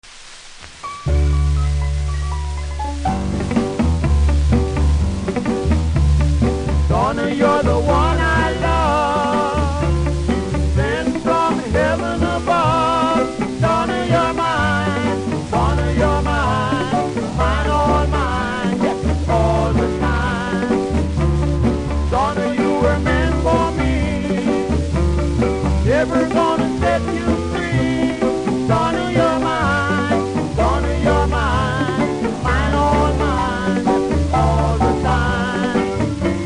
CONDITION：VG ( WOL, HISS )
キズによるノイズは少なめですがプレス起因のヒスあります。